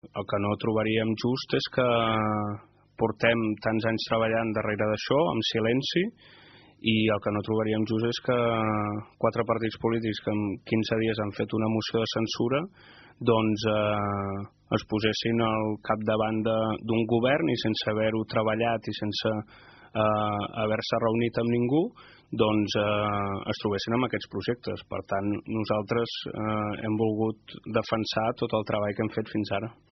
En una entrevista ahir al vespre a Ràdio Palafolls, el socialista i ara cap de l’oposició Joan Mercader, assegurava que el seu futur polític es mantindria a l’oposició de l’Ajuntament Malgratenc.